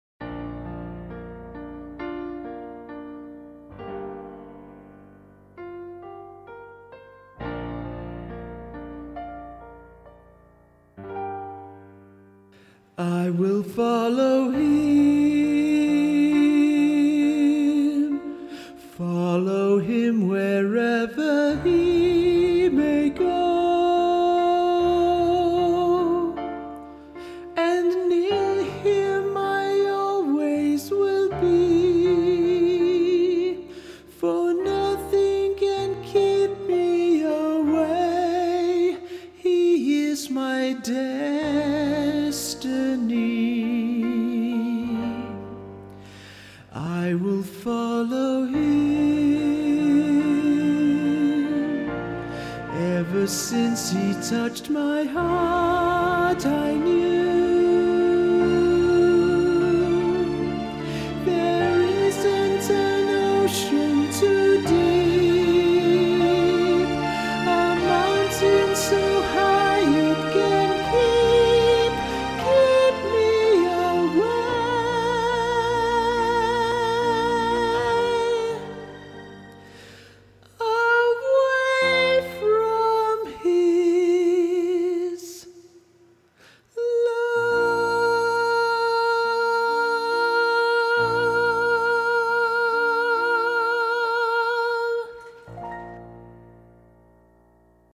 Choir
Sister Act - Middle Part
I-Will-Follow-Him-intro-MIDDLE-PART.mp3